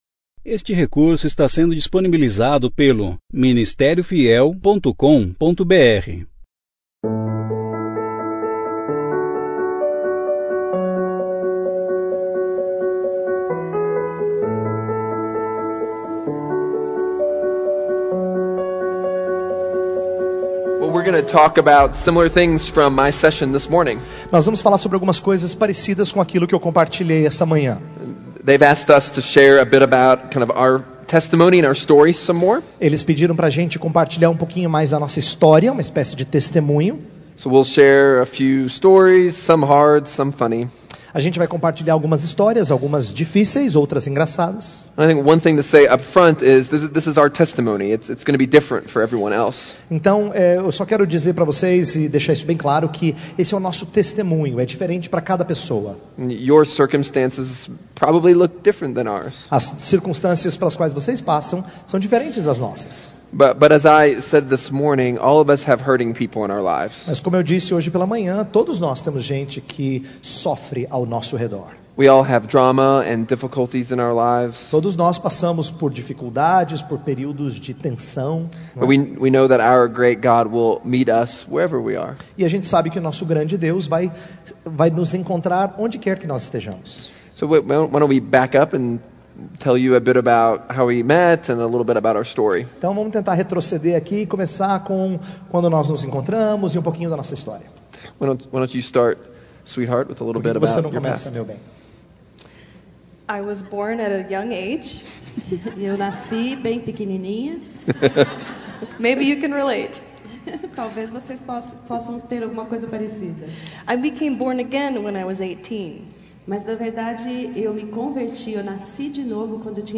Conferência: 2ª Conferência Fiel para Mulheres – Brasil Tema: Nosso Maior Tesouro Ano: 2017 Mensagem
Mesa Redonda 2 – Como amar aqueles que estão sofrendo